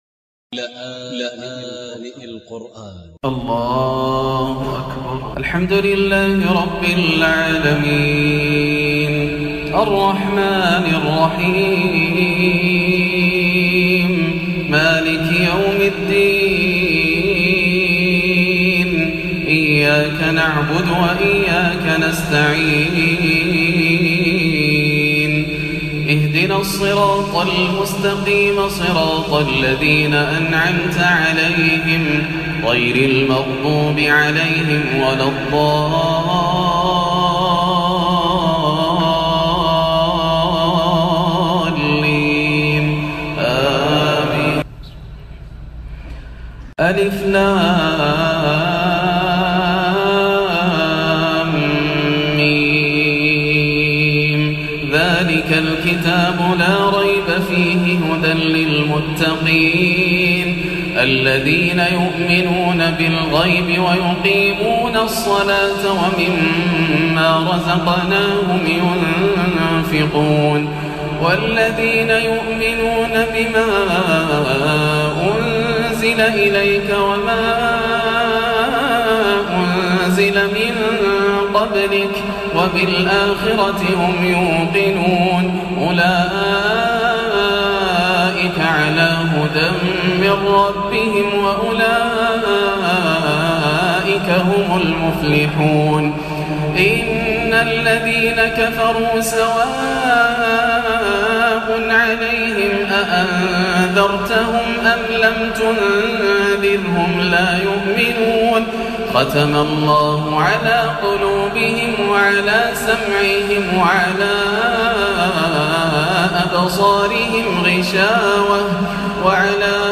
الليلة اليتيمة في الرياض بعد تكليف شيخنا الغالي في الحرم المكي - الليلة الأولى رمضان 1438 > الليالي الكاملة > رمضان 1438هـ > التراويح - تلاوات ياسر الدوسري